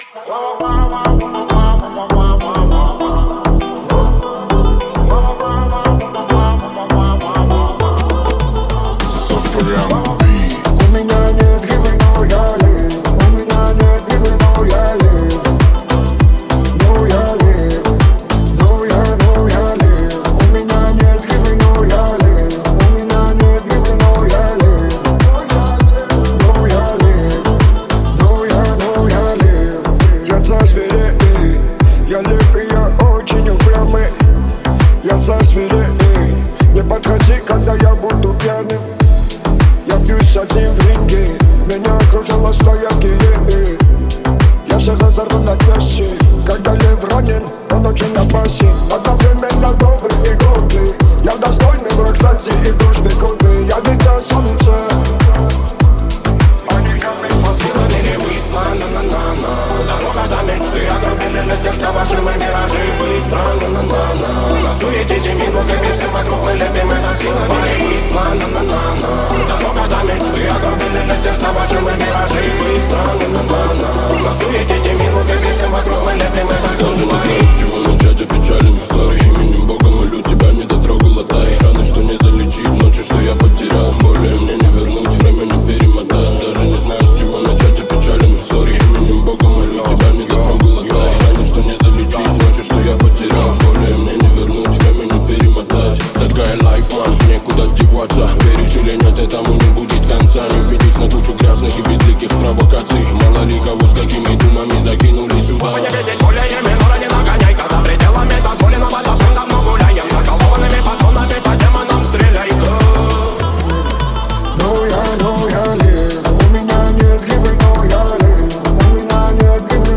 • Категория: Новые ремиксы